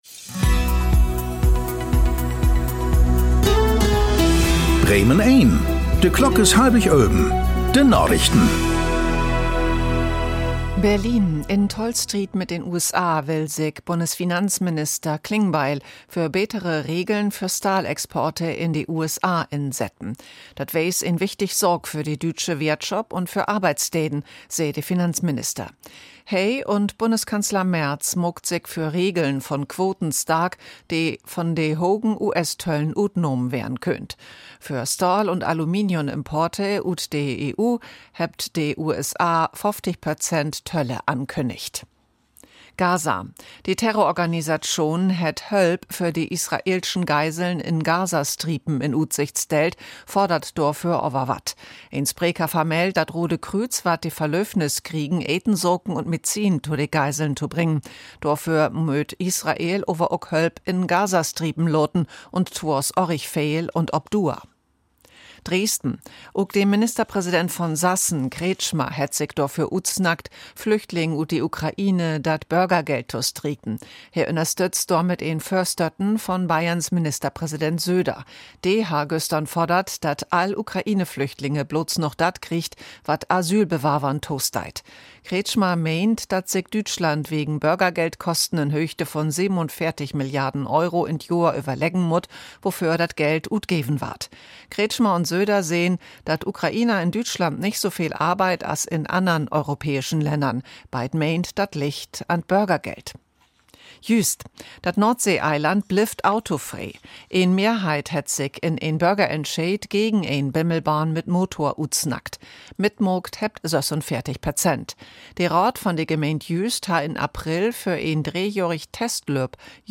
… continue reading 1109 つのエピソード # Tägliche Nachrichten # Nachrichten # Thu Apr 01 11:24:10 CEST 2021 Radio Bremen # Radio Bremen